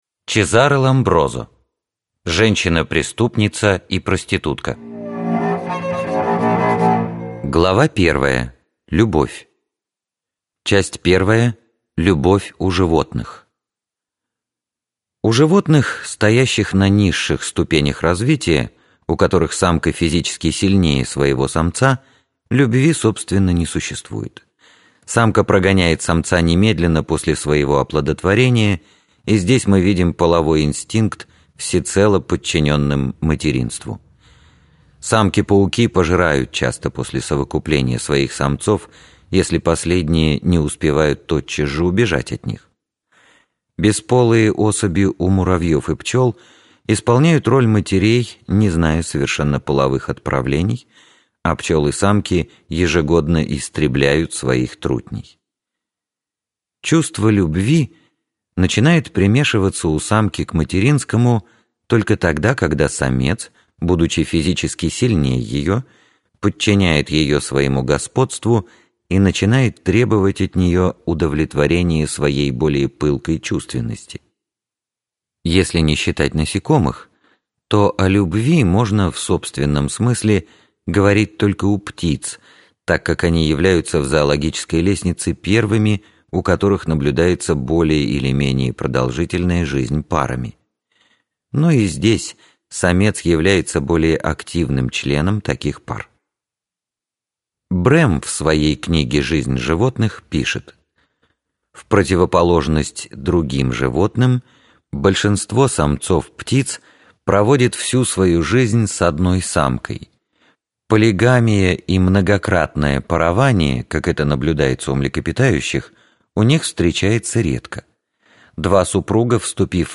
Аудиокнига Женщина – преступница и проститутка | Библиотека аудиокниг